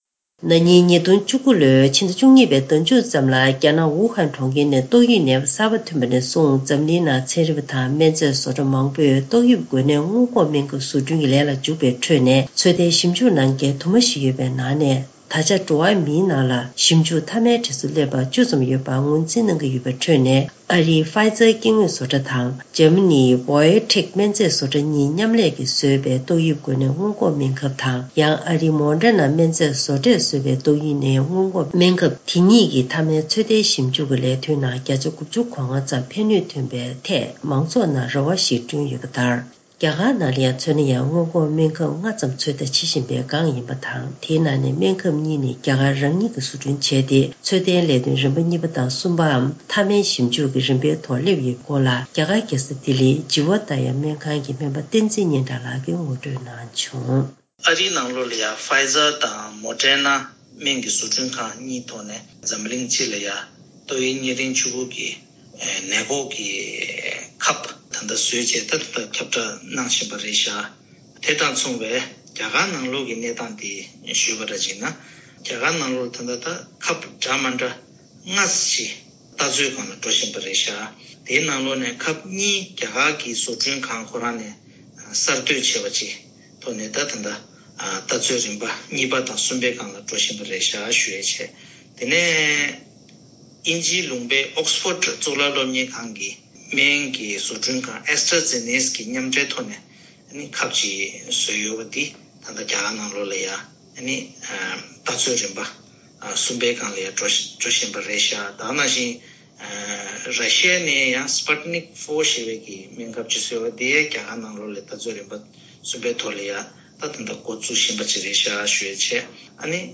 བཀའ་འདྲི་ཞུས་ཏེ་ཕྱོགས་བསྒྲིགས་ཞུས་པ་ཞིག་གསན་རོགས་གནང་།།